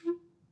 Clarinet
DCClar_stac_F3_v1_rr1_sum.wav